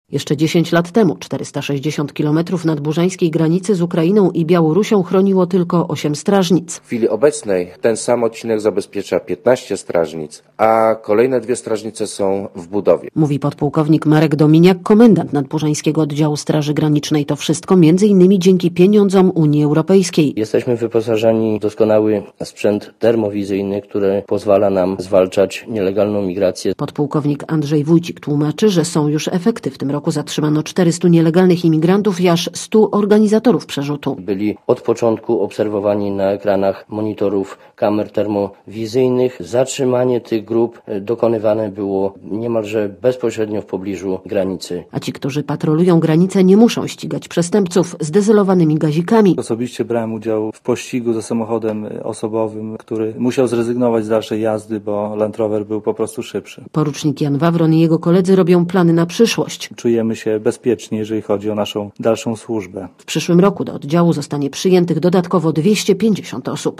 (RadioZet) Źródło: (RadioZet) Posłuchaj relacji